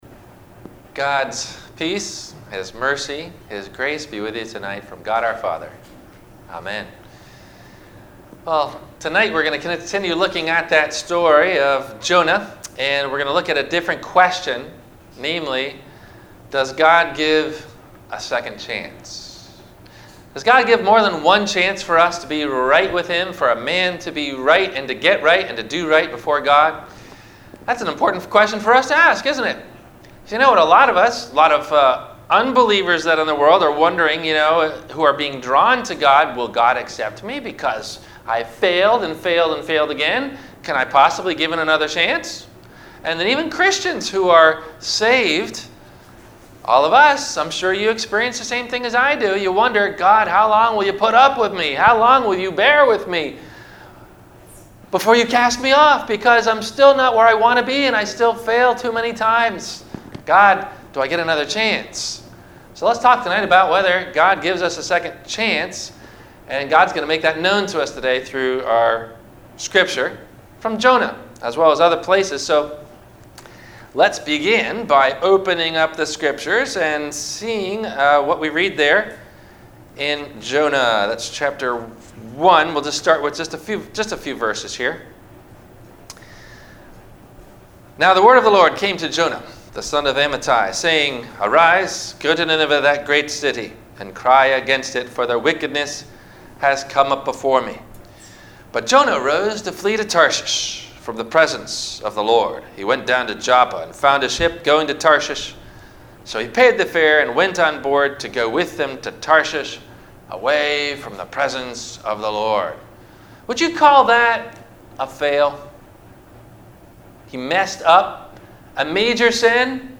Does God Give Us A Second Chance? – Sermon – Wed Lent